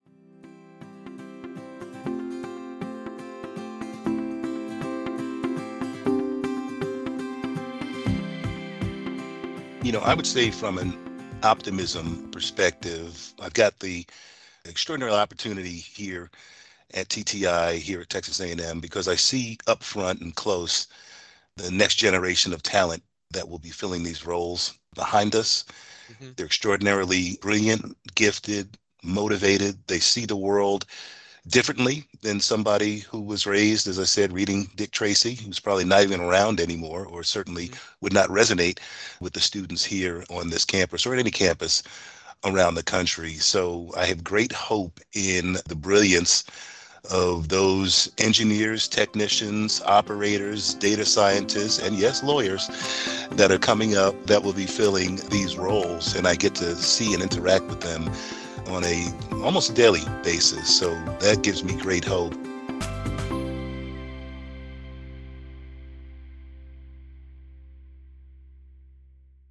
In this clip Winfree speaks about the inspiration he takes from seeing the transportation leaders of tomorrow learning about mobility systems at Texas A&M – but to hear the whole episode, including more from Winfree and insight from Bhatt as to why he believes a new era is underway for transportation infrastructure in the USA don’t miss the full episode, which you can find at the Thinking Transportation homepage or on your favorite streaming service.